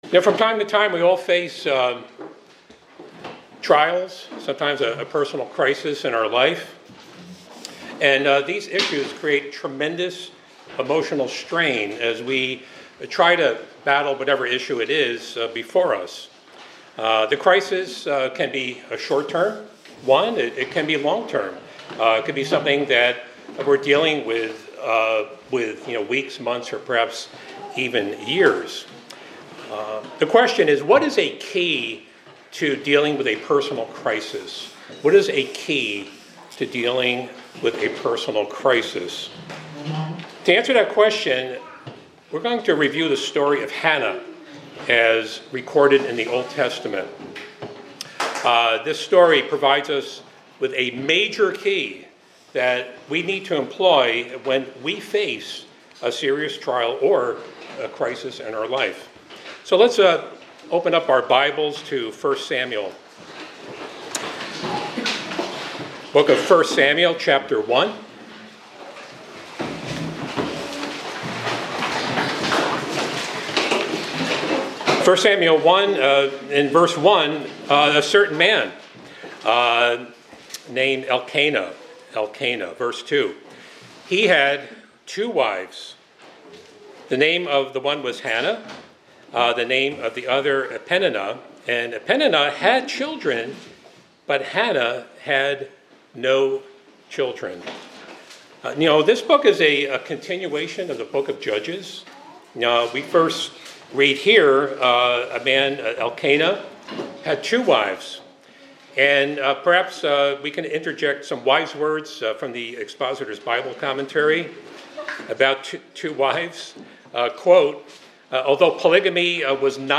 This sermonette focuses on the story of Hannah from the Old Testament (1 Samuel 1). The speaker discusses how everyone faces personal crises, sometimes lasting for years, and explores how to cope with such challenges by examining Hannah’s experience.
Given in Hartford, CT